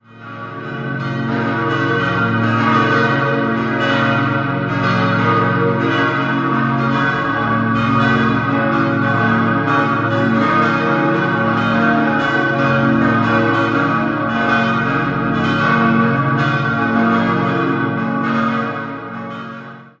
Das Kirchenschiff wurde in der Barockzeit nach einem Brand wieder neu errichtet. 5-stimmiges Geläut: b°-des'-es'-ges'-b' Die Glocken wurden 1932 von der Gießerei Rüetschi in Aarau gegossen.